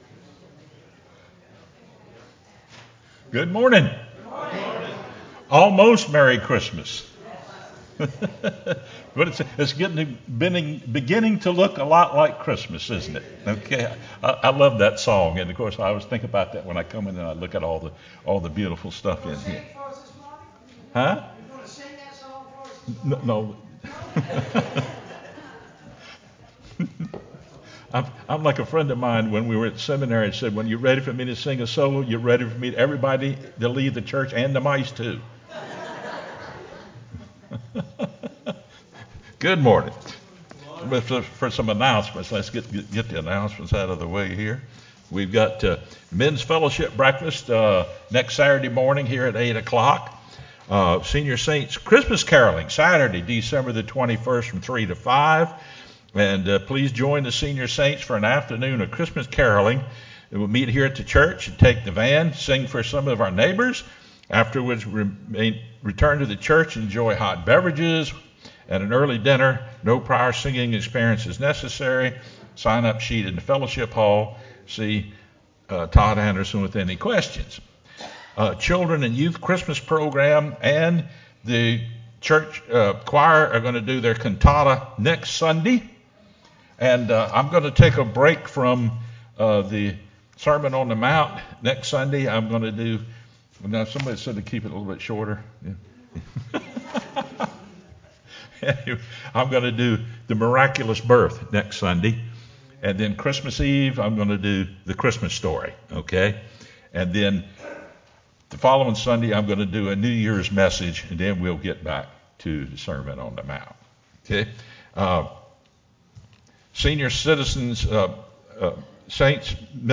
sermonDec15-CD.mp3